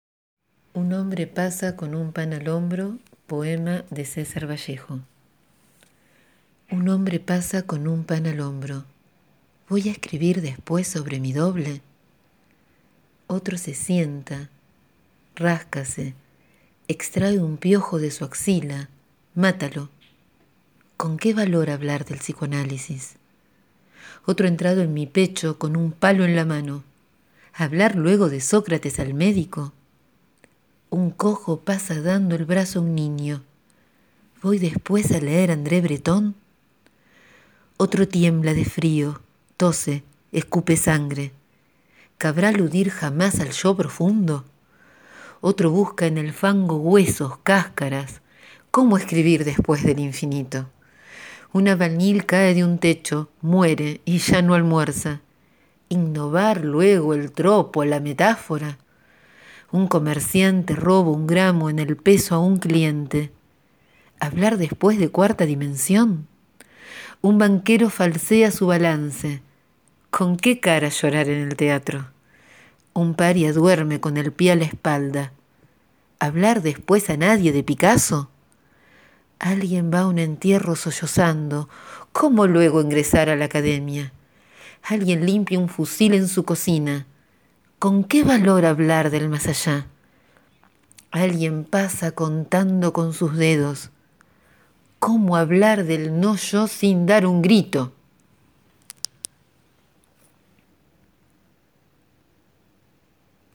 Abrazos y el poema en mi voz